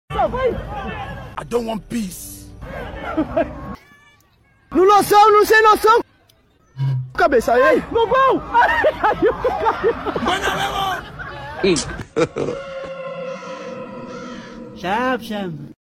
Local soccer or football practice.